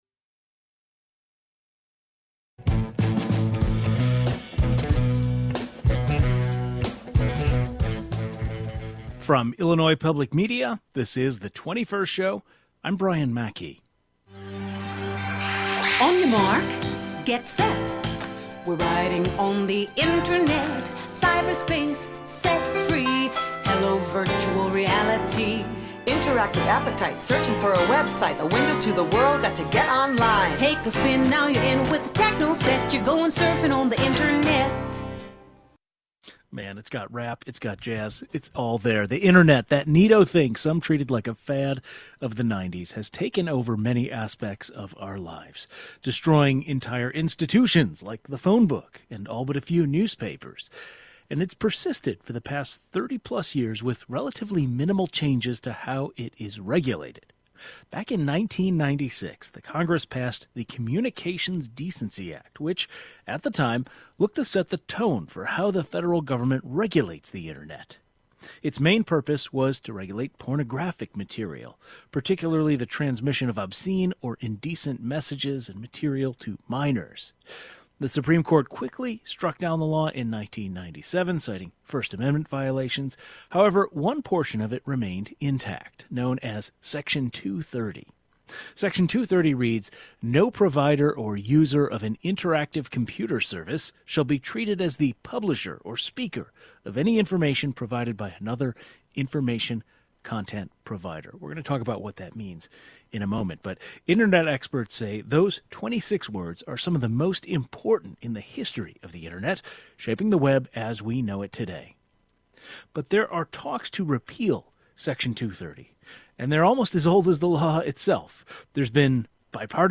Legal and media experts weigh in.